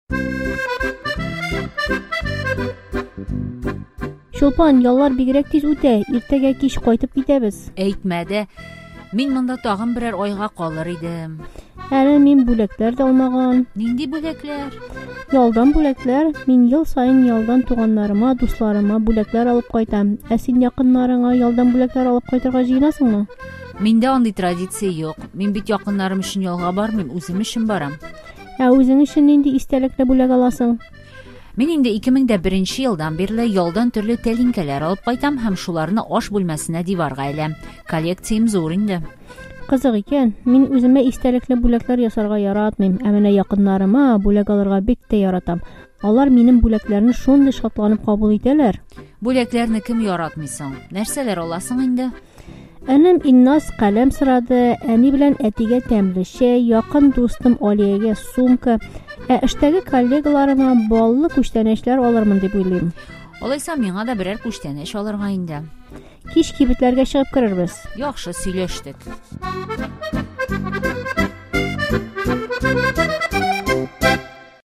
Проверка на понимание диалога. Ответьте на следующие вопросы: